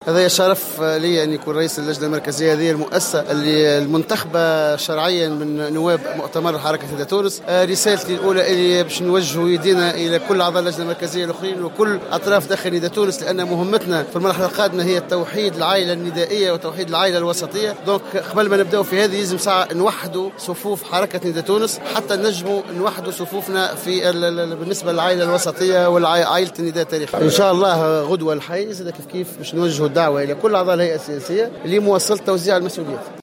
وفي أولى تصريحاته بعد انتخابه قال سفيان طوبال لمراسلتنا إنه سيسعى الى توحيد صفوف الحزب كما سيتم غدا المرور إلى توزيع المهام صلب اللجنة المركزية.